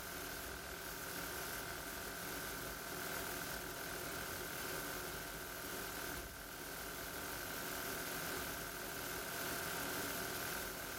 A spike at 1.25 KHz, and the second highest peak is at 1.6 KHz.
I have recorded the signals shown above, but please keep in mind that I’ve enabled Automatic Gain Control (AGC) to do so to make it easier for you to reproduce them.
30% Fan Speed